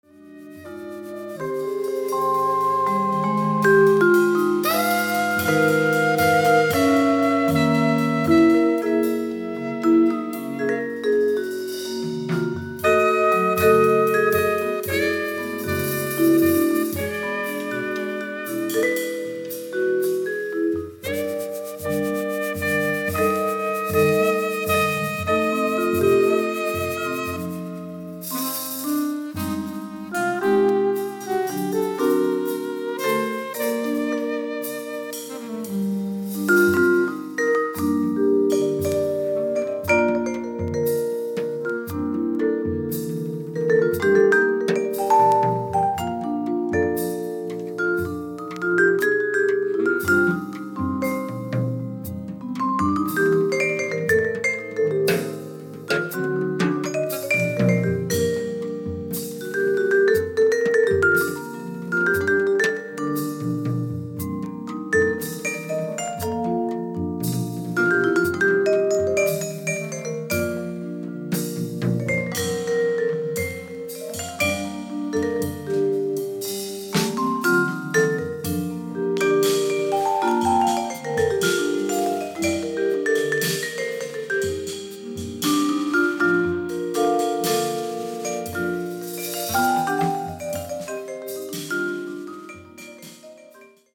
Alto Saxophone
Bass
Drums
Vibraphone